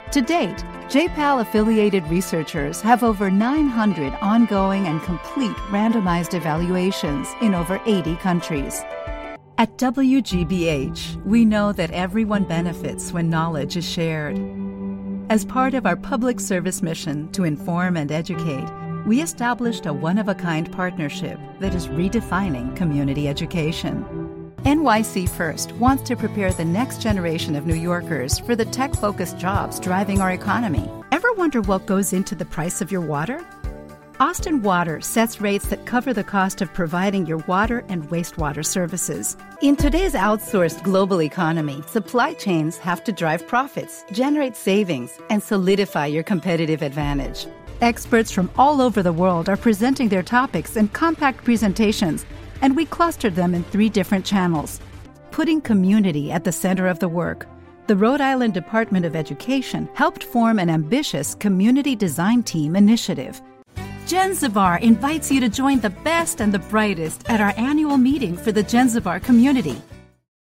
Vídeos corporativos
Mis clientes describen mejor mi voz como amigable, chispeante, expresiva, agradable, cálida y entusiasta.